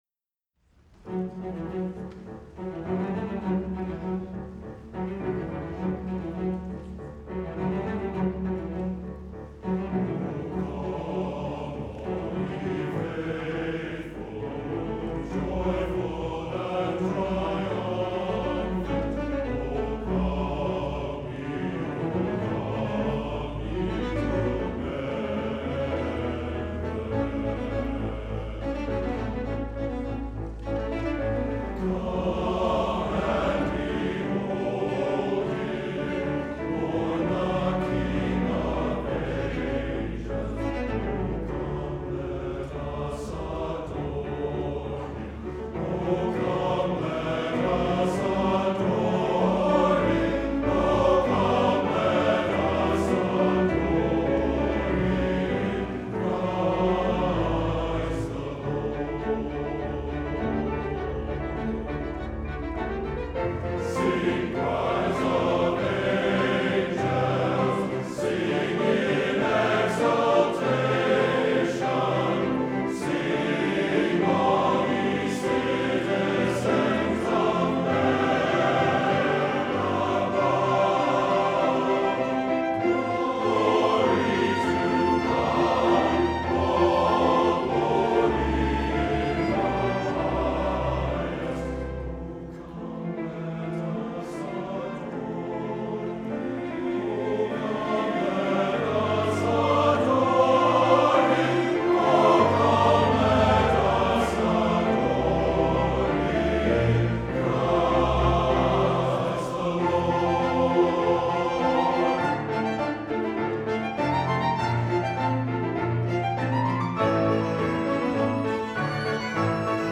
Fall 2019 — Minnesota Valley Men's Chorale